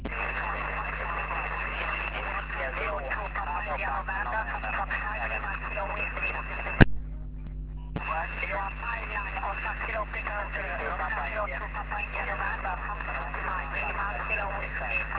Meteor Scatter
But the activity was great, some pile-ups, QRM as usually, so I worked about 100 QSOs, all random SSB.
Used rig: TCVR R2CW, PA 500W, ant: 4x9el (North-East), 4x4el (West) and 4x4el (South).